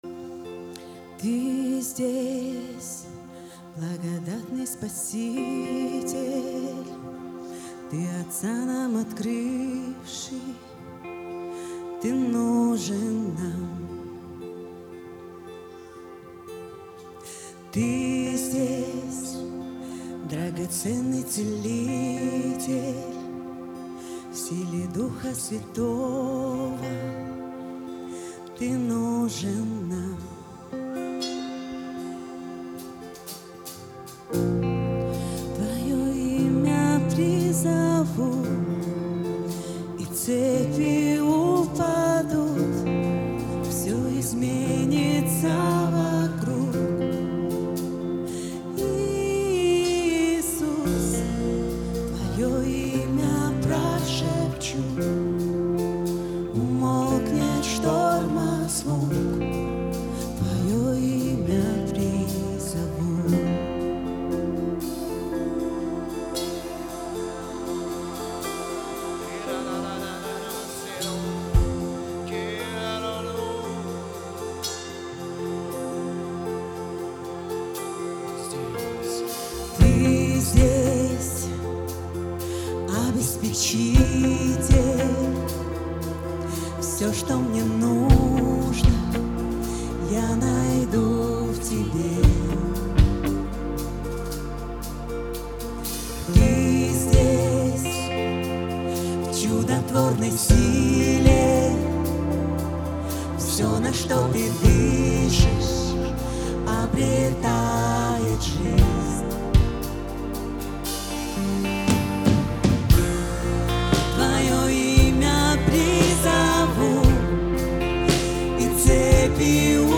59 просмотров 16 прослушиваний 0 скачиваний BPM: 116